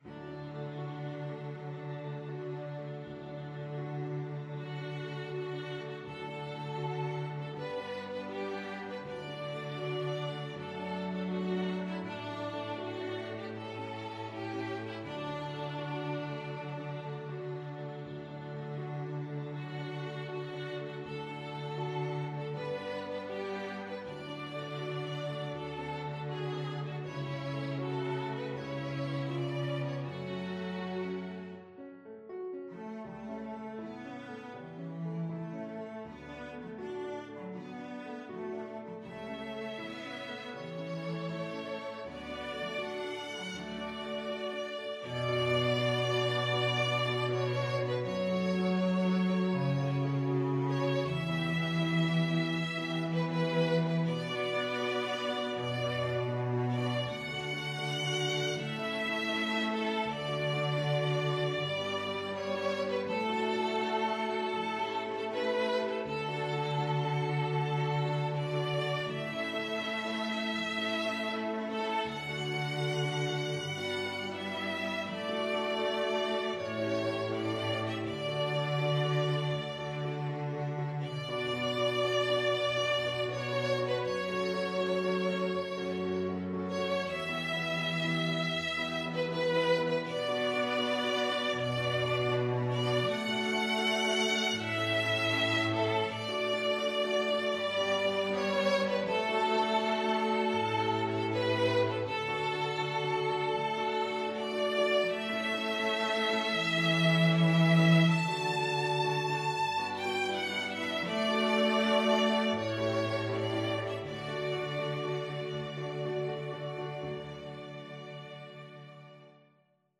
Christmas
Violin 1Violin 2ViolaCelloPiano
Andante
4/4 (View more 4/4 Music)